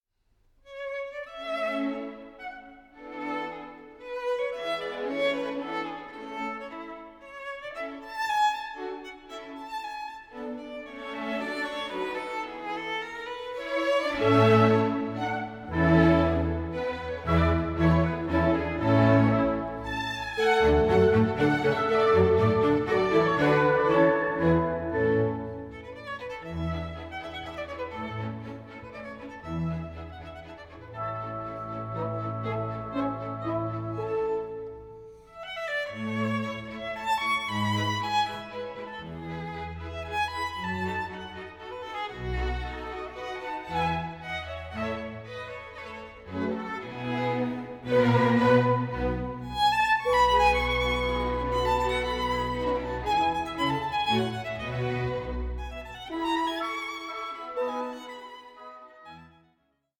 Adagio 10:59